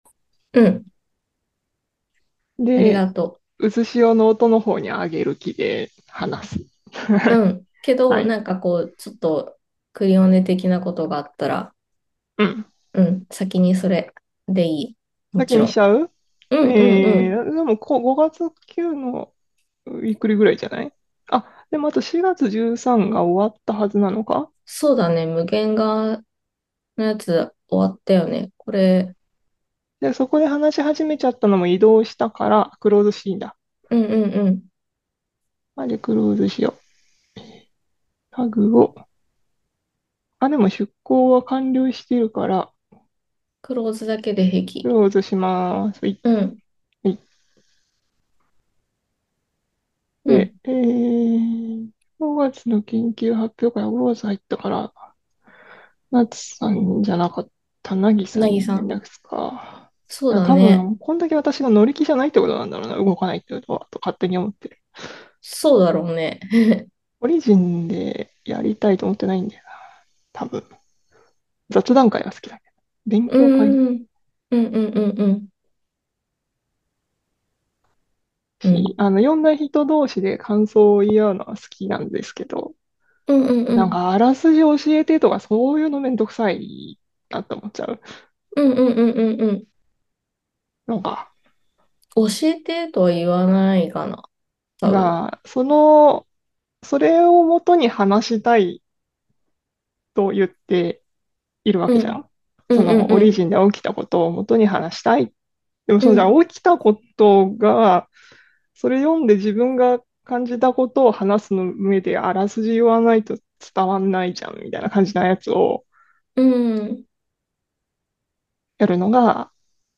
Audio Channels: 2 (stereo)